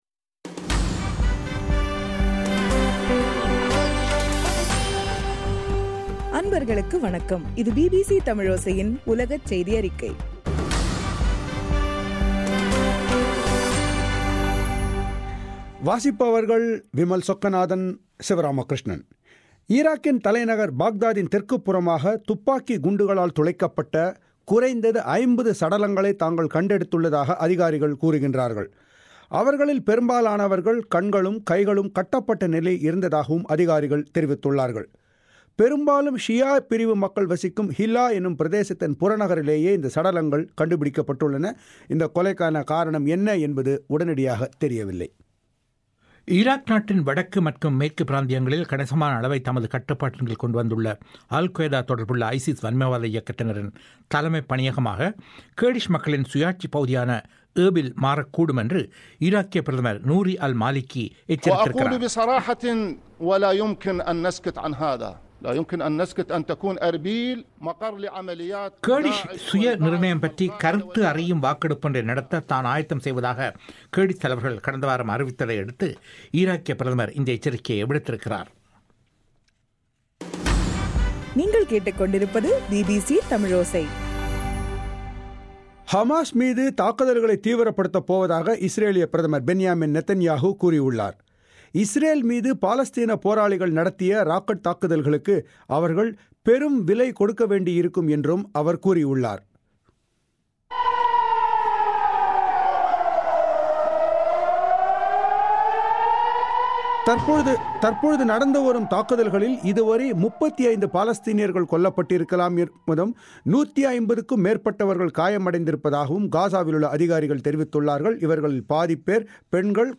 ஜூலை 9 2014 பிபிசி தமிழோசையின் உலகச் செய்திகள்